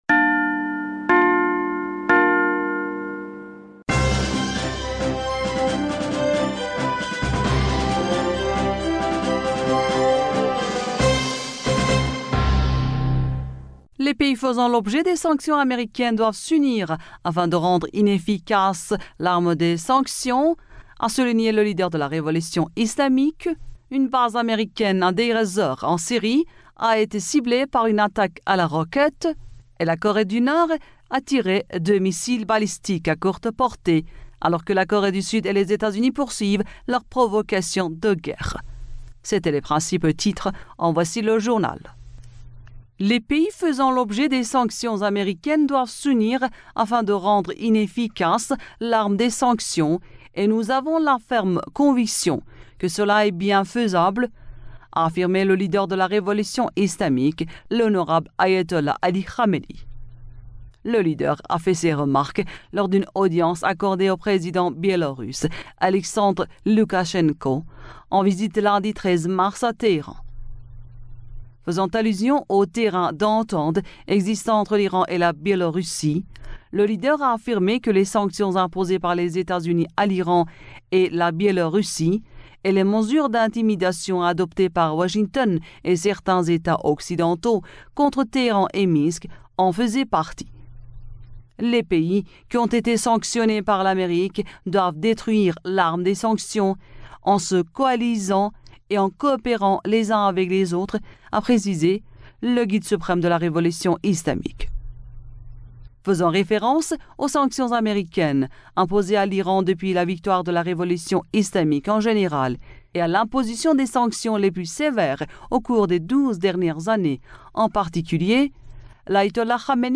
Bulletin d'information du 14 Mars